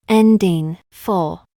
Ending -FLE is a C+LE pattern at the end of a word that is not a suffix, and if removed, will not have a base word, as in “shuffle”. Ending /fəL/, Ending /fəL/, Ending /fəL/, shuffle.
FLE-shuffle-phoneme-name-AI.mp3